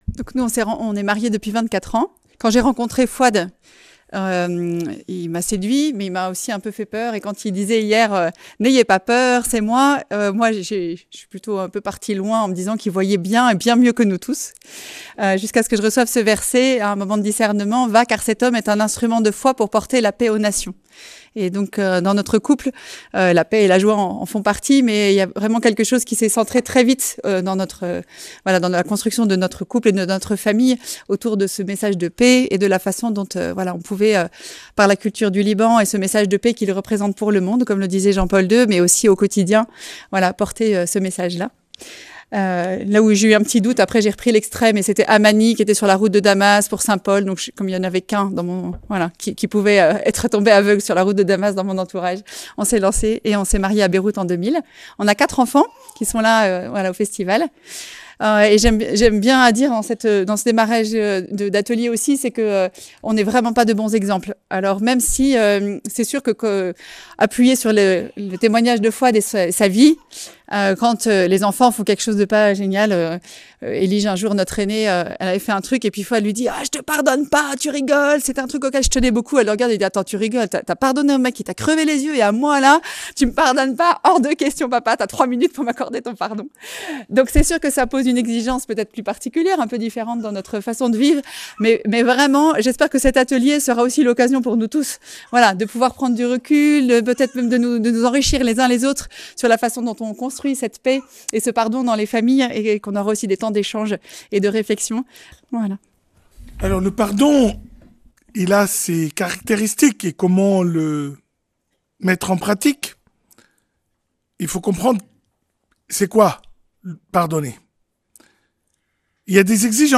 ND du Laus. Festival Marial 2024
Conférence de la semaine